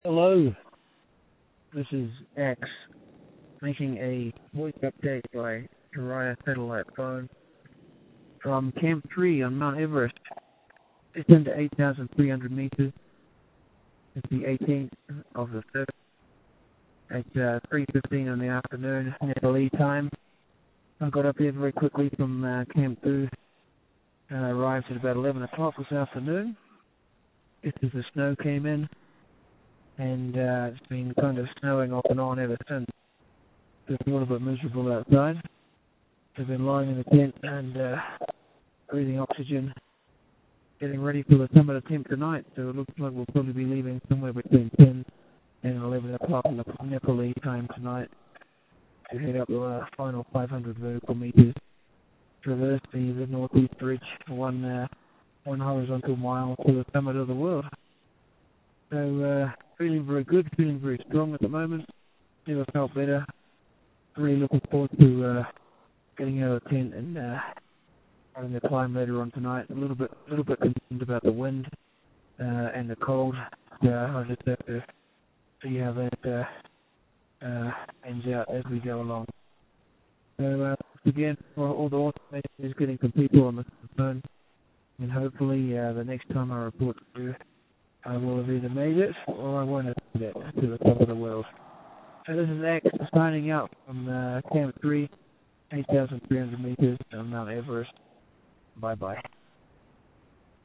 You’re sounding strong and healthy – keep it up!